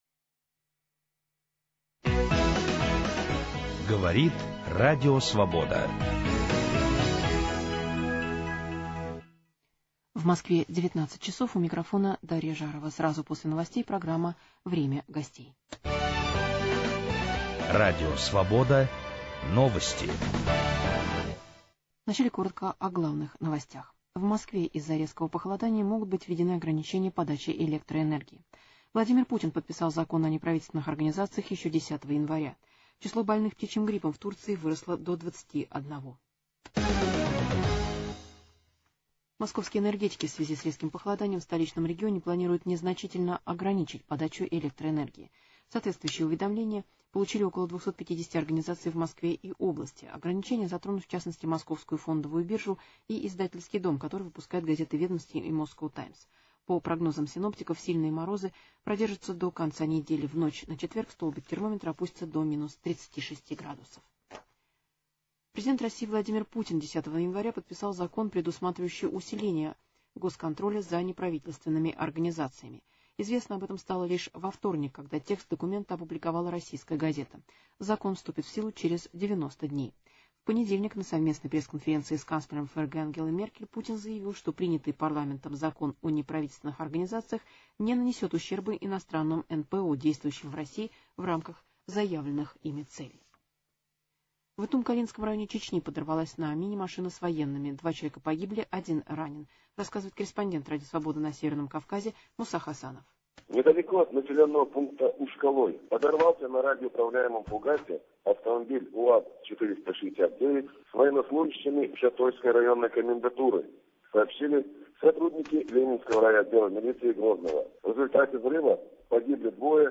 Известные и интересные люди ведут разговор о стране и мире, отвечают на вопросы в прямом эфире. Круг вопросов - политика, экономика, культура, права человека, социальные проблемы.